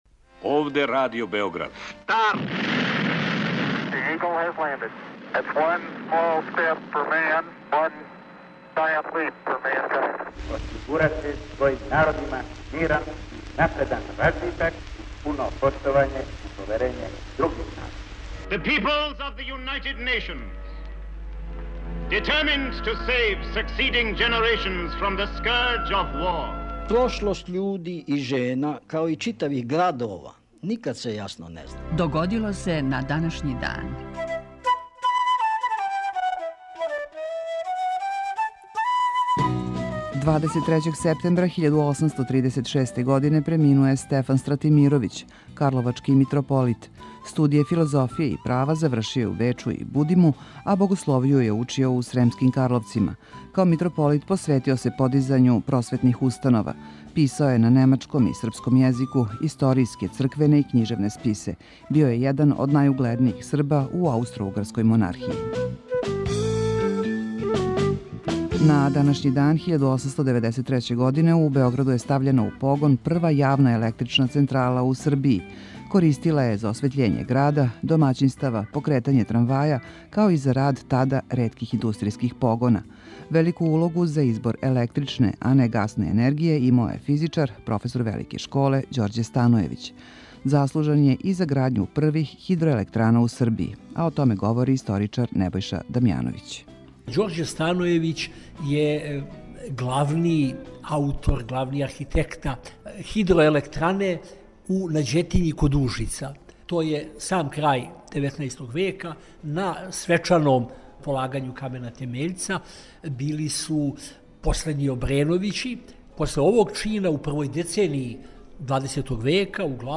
Емисија Догодило се на данашњи дан, једна од најстаријих емисија Радио Београда свакодневни је подсетник на људе и догађаје из наше и светске историје. У 5-томинутном прегледу, враћамо се у прошлост и слушамо гласове људи из других епоха.